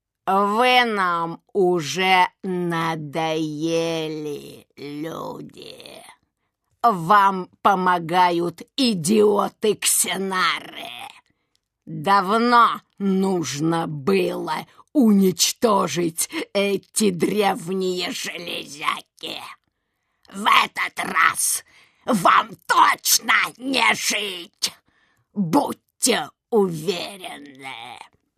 Примеры озвучания: